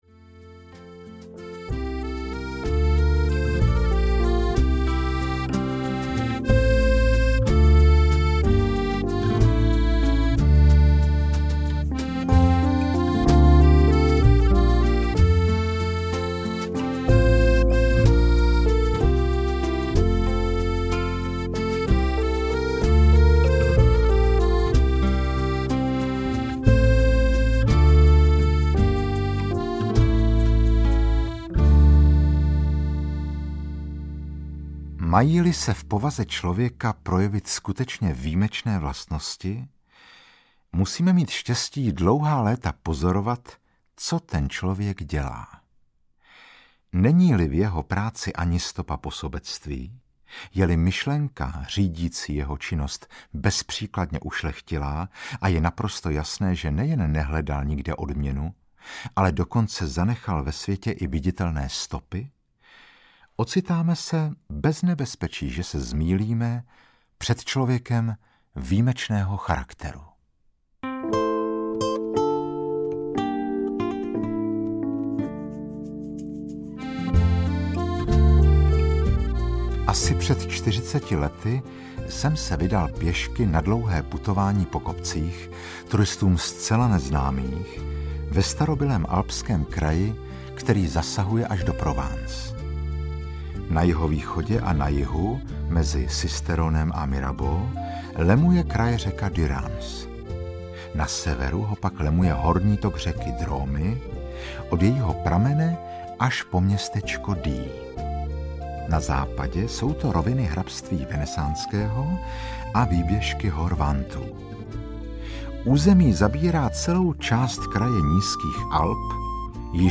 Muž, který sázel stromy audiokniha
Ukázka z knihy
• InterpretMarek Eben